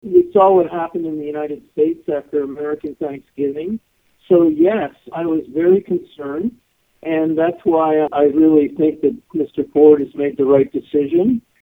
The Acting Medical Officer of Health is very concerned with COVID-19 numbers rising after the holidays and says Premier Ford made the right decision by implementing the lockdown.